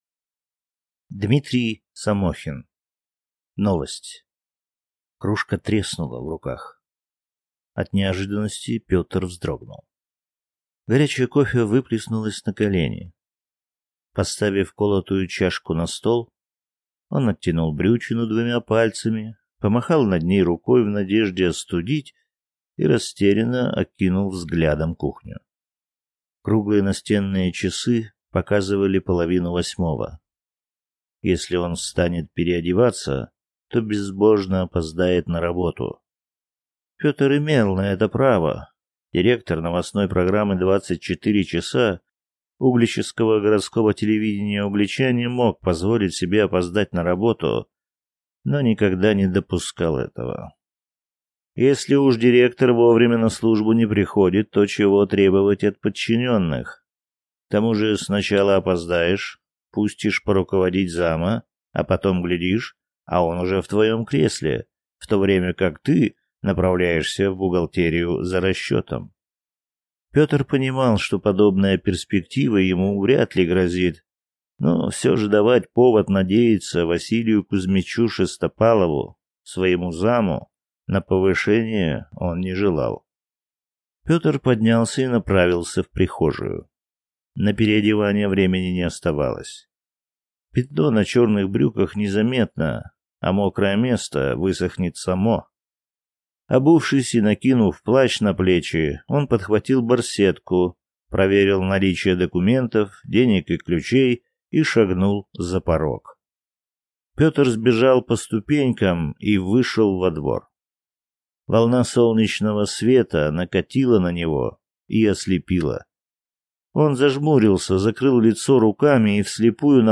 Аудиокнига Новость | Библиотека аудиокниг
Прослушать и бесплатно скачать фрагмент аудиокниги